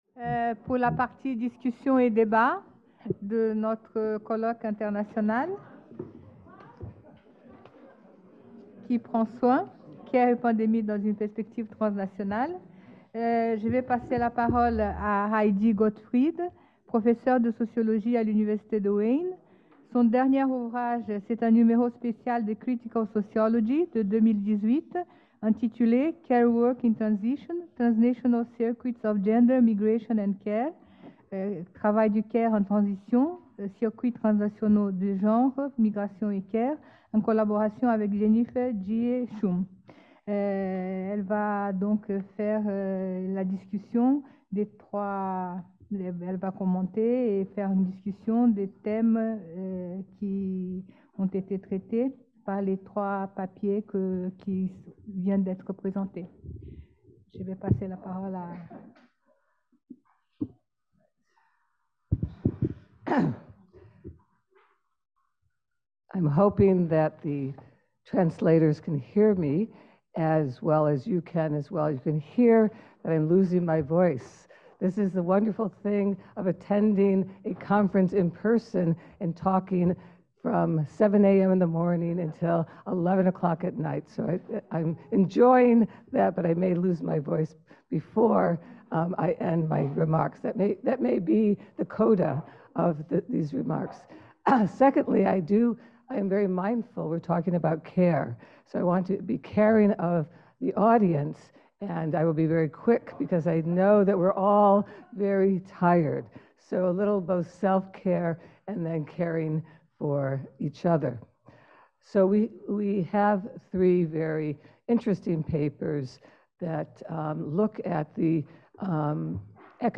Ce colloque met en lumière les expériences vécues des travailleuses du care dans les hôpitaux, maisons de retraite, ou à domicile mais aussi d’interroger les réponses des États et autres acteurs sociaux, en identifiant les réactions communes et les contrastes parfois saisissants entre les stratégies à l’œuvre. Ce colloque réunit des spécialistes en sciences sociales de différentes disciplines (sociologie, géographie, psychologie…) qui fournissent des éclairages complémentaires sur cette période